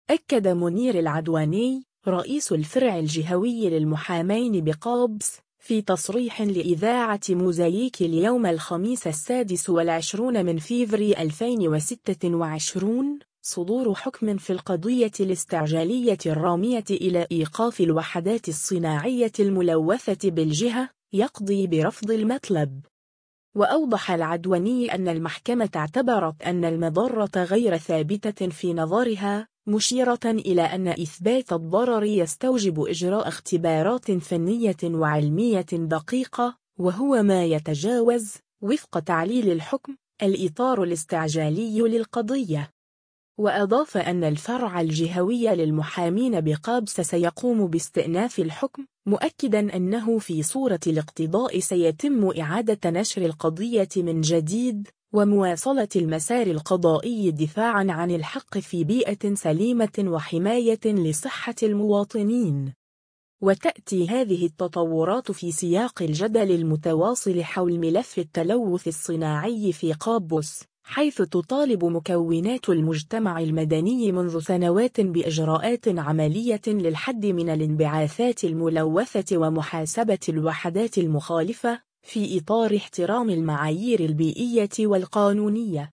في تصريح لإذاعة موزاييك